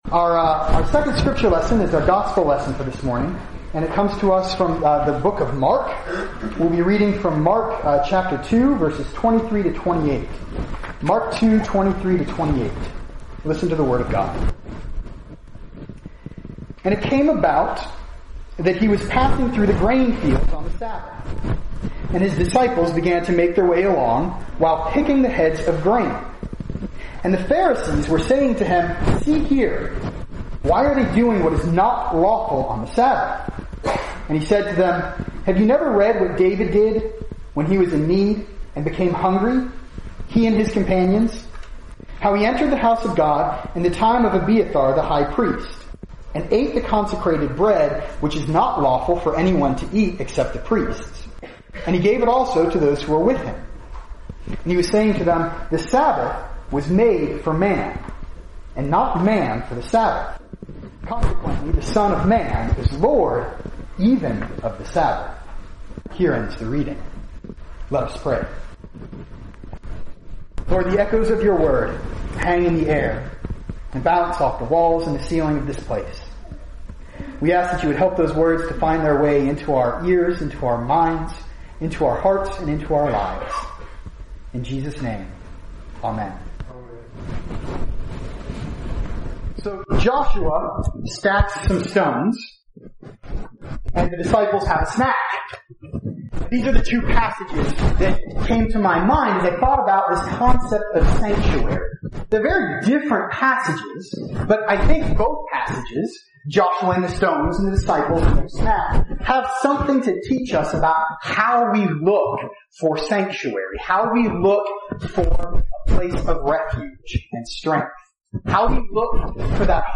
Sermons & Speeches
Salem has a history of recording remarks given by those who address the congregation at the annual reunion.